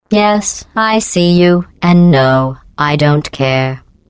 glados_dreame_voice_pack_customized